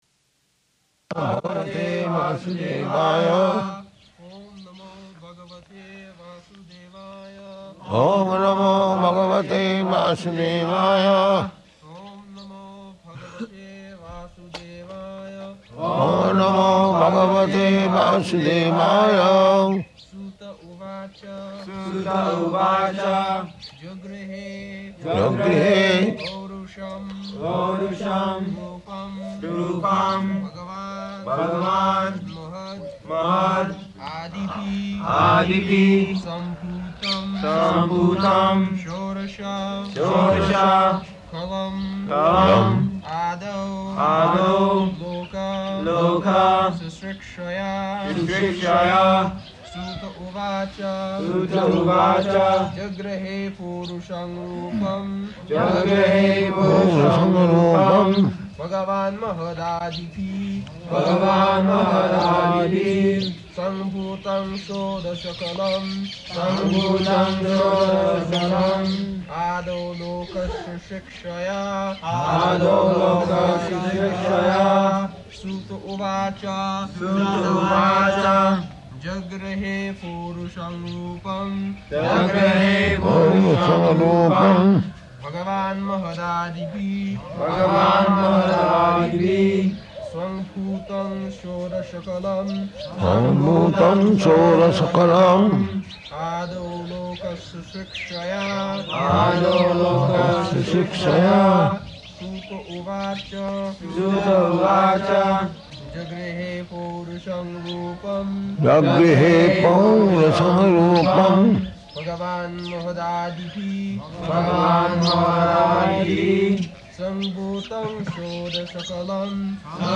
November 14th 1972 Location: Vṛndāvana Audio file
[leads chanting of verse, etc.] [Prabhupāda and devotees repeat]